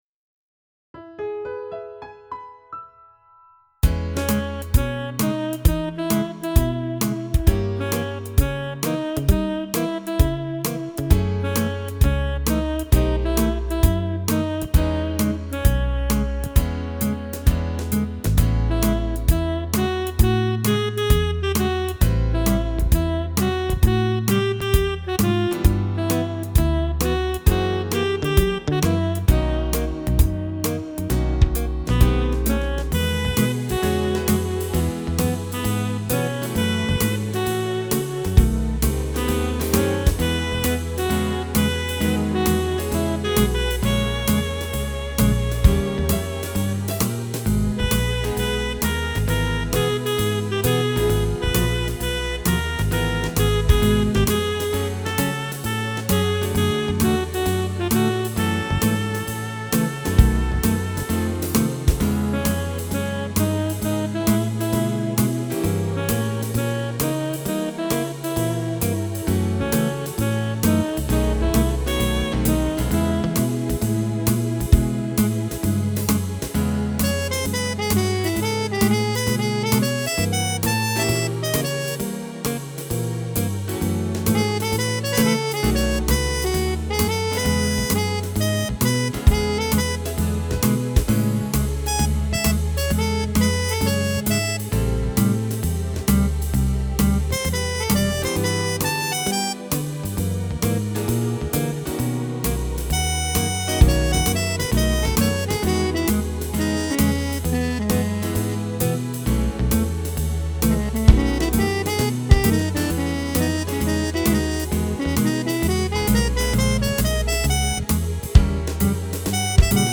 This calls for a little more laid-back approach.